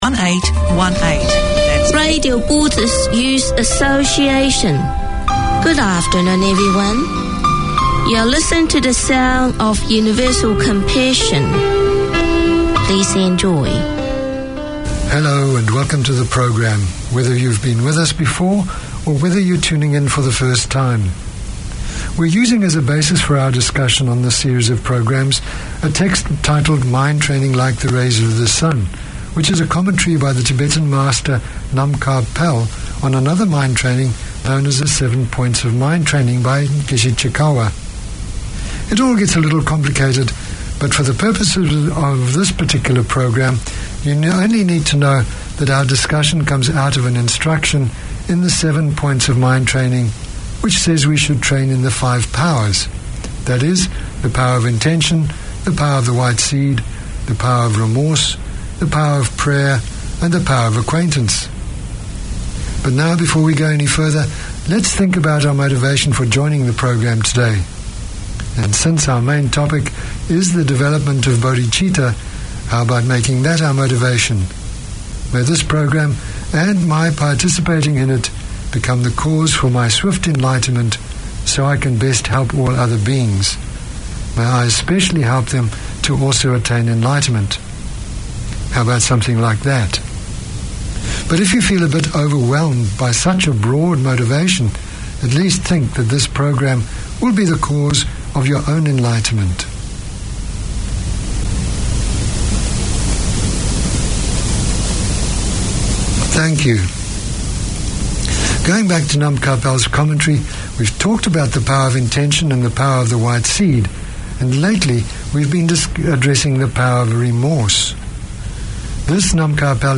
The radio show aims to open discussion on the progress of former refugees in Aotearoa/NZ through interviews, debate, news and talkback. Each week Resett Radio welcomes guests, listens to their stories and music and explores current affairs as they relate to resettlement communities today.